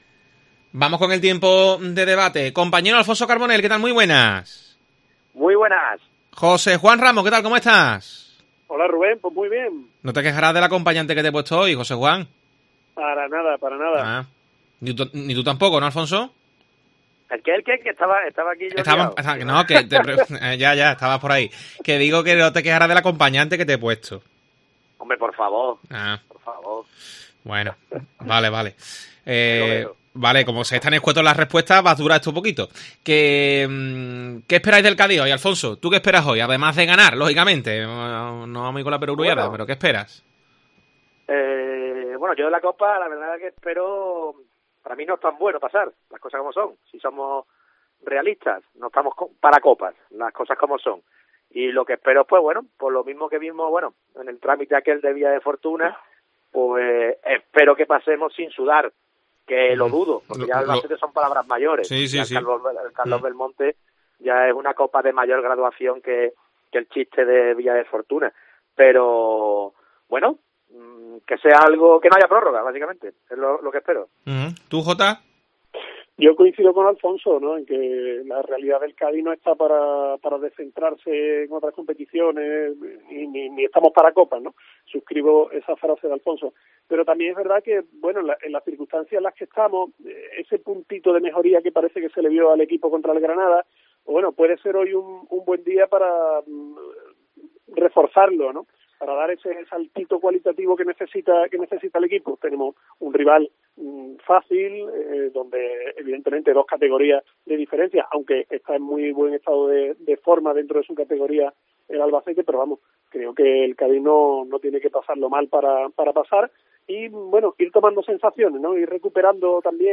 El debate de Deportes COPE sobre el Cádiz CF